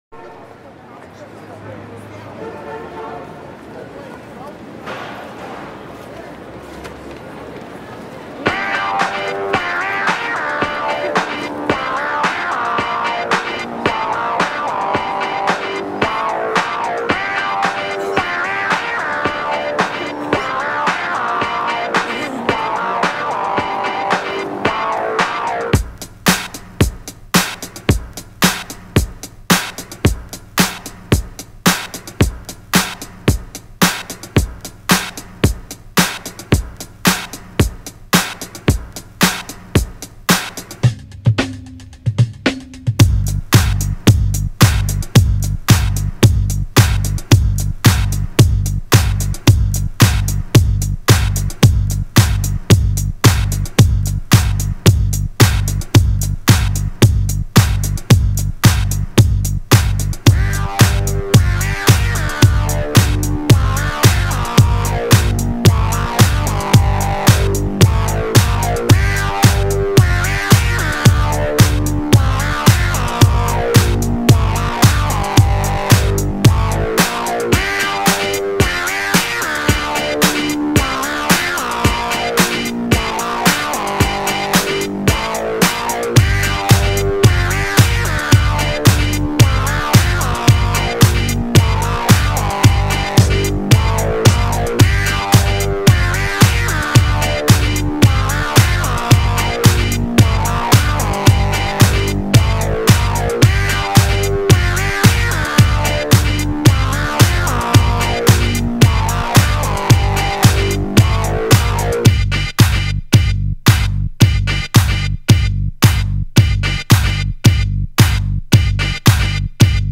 Electronic dance music
Disco music